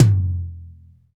TOM RLTOM311.wav